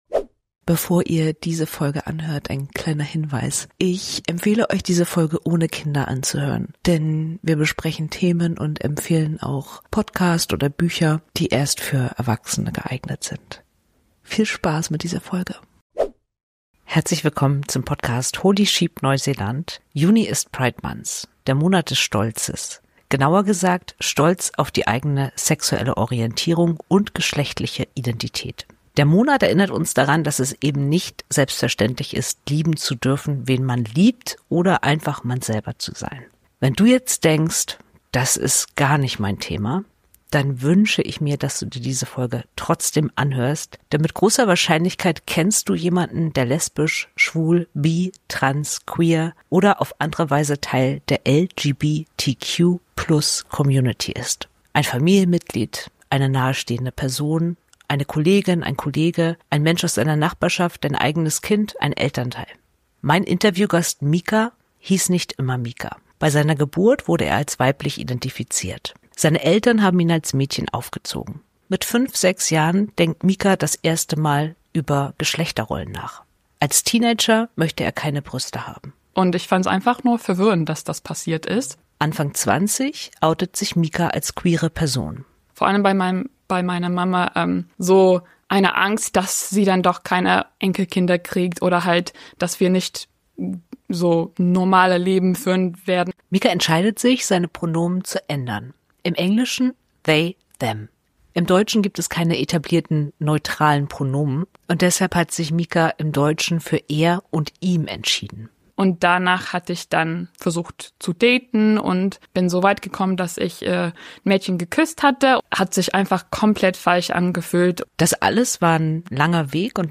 Ein ganz besonderes Interview über Asexualität, eine Perspektive, die in der öffentlichen Debatte bislang kaum vorkommt.